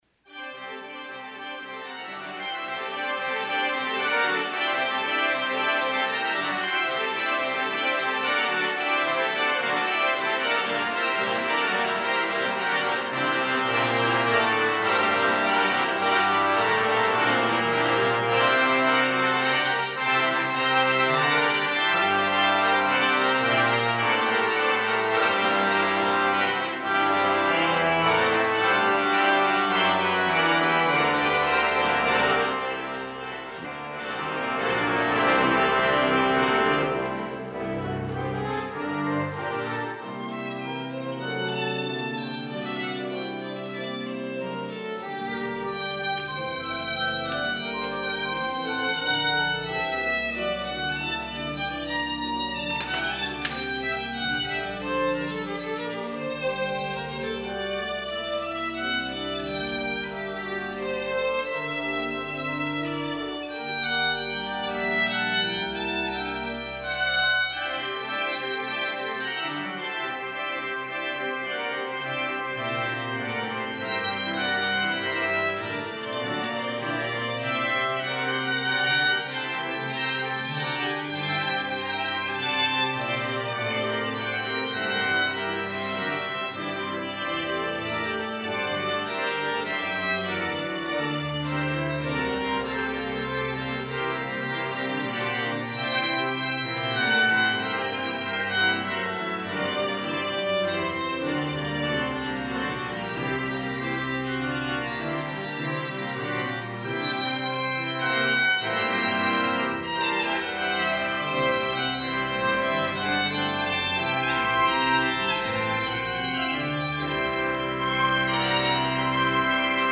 violin
organ